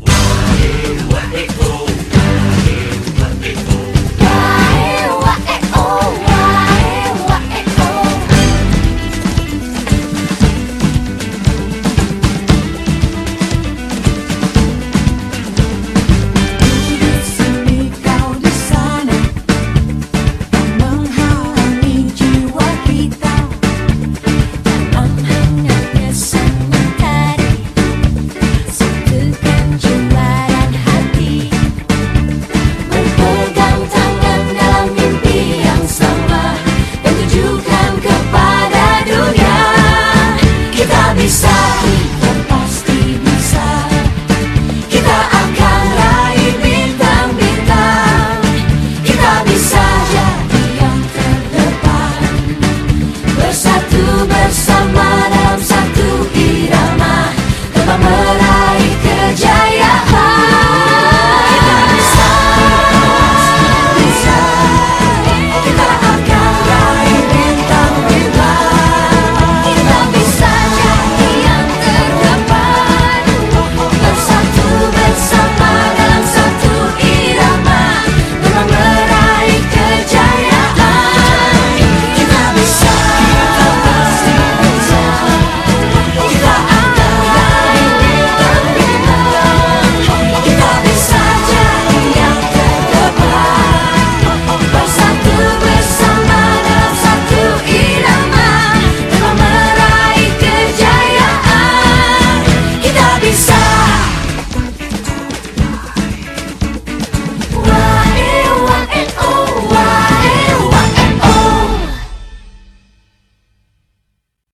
BPM116
Audio QualityPerfect (High Quality)